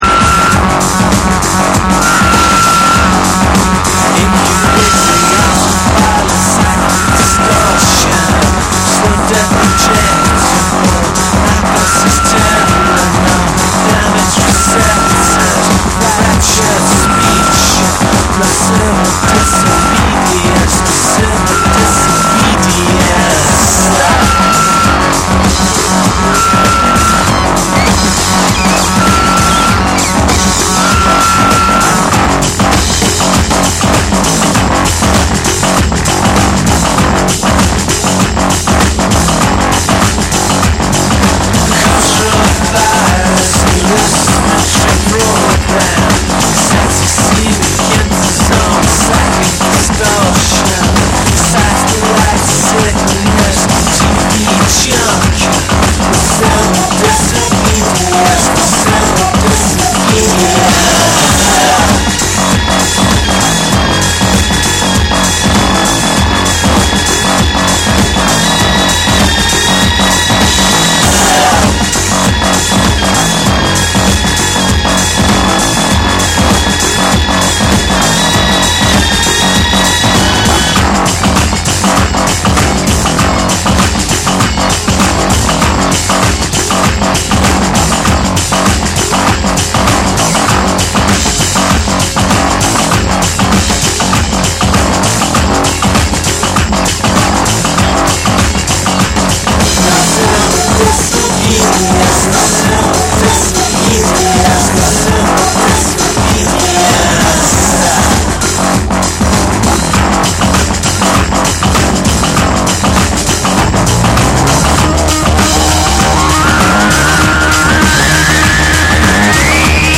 ロックとエレクトロニクス、ダブの感覚が交錯する、当時のバンドの先鋭的なサウンドを象徴する一枚。
NEW WAVE & ROCK